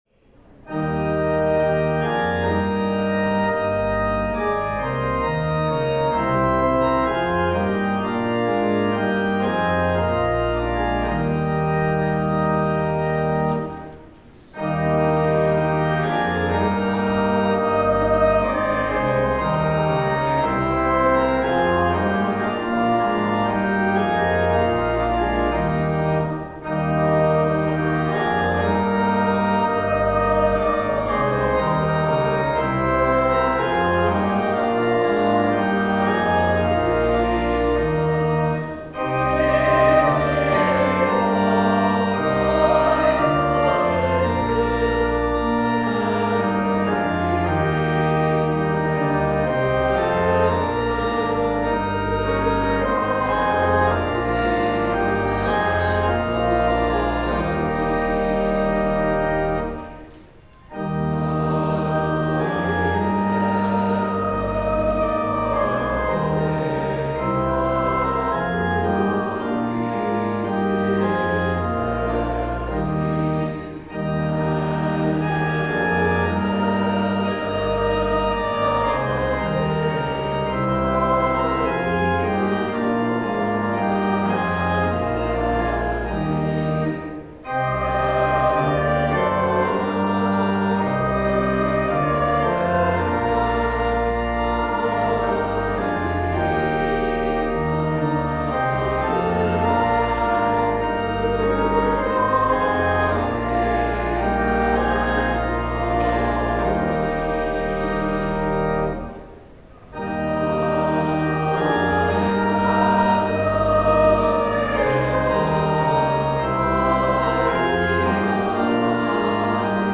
Hymn 310
Sermon
The Lord’s Prayer (sung)